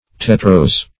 \Tet"rose\
tetrose.mp3